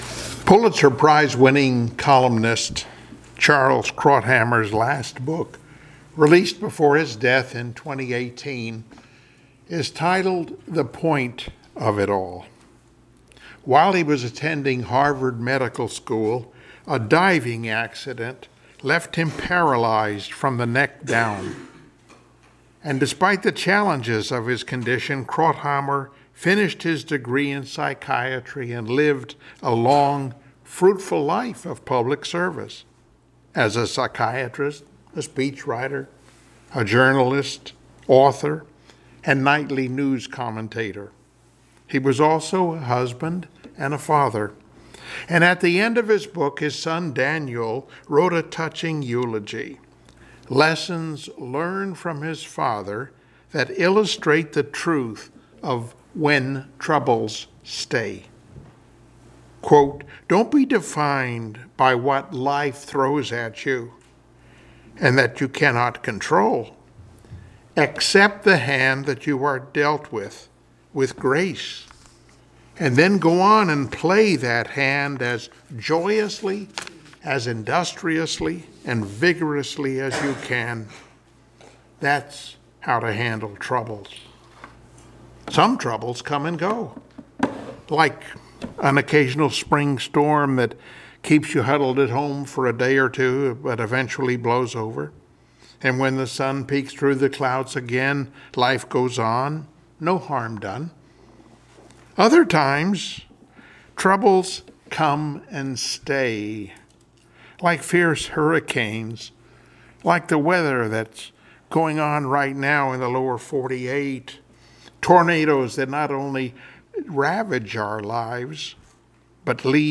Service Type: Sunday Morning Worship Topics: Joseph's Example , The Bible's Realistic Portrayal , When We've handled Troubles Correctly